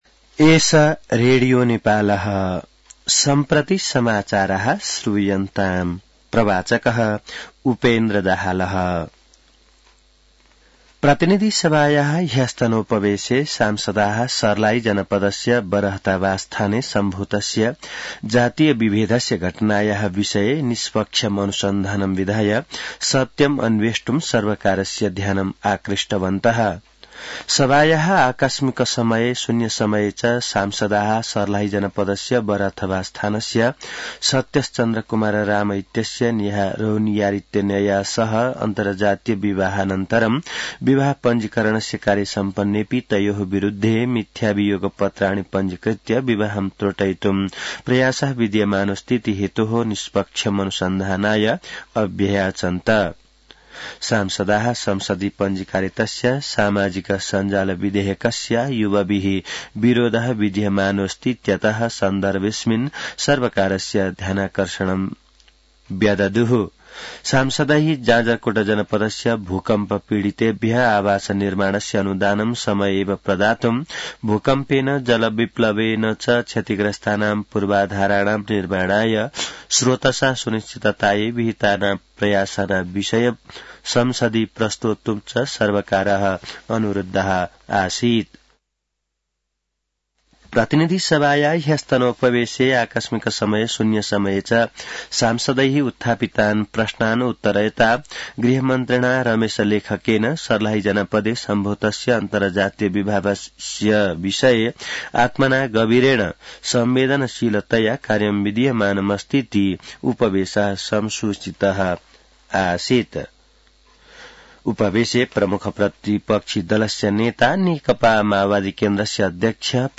संस्कृत समाचार : २६ माघ , २०८१